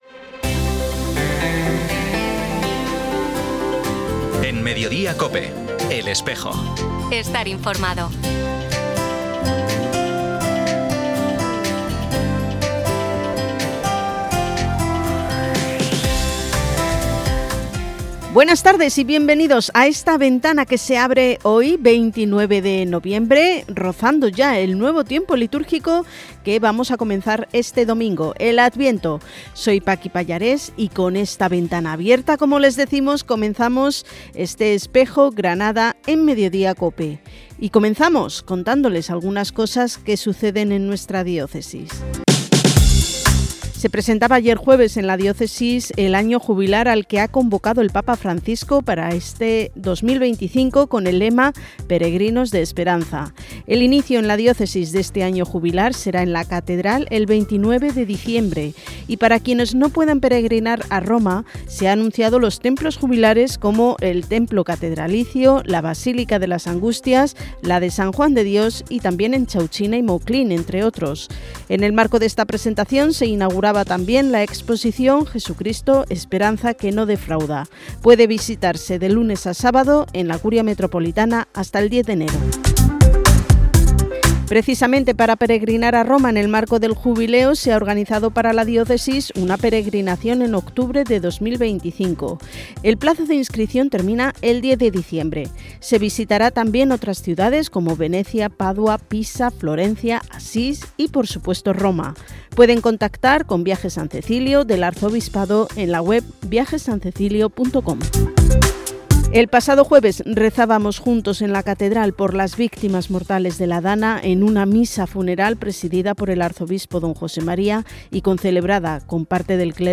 Programa emitido en COPE Granada y COPE Motril el 29 de noviembre de 2024.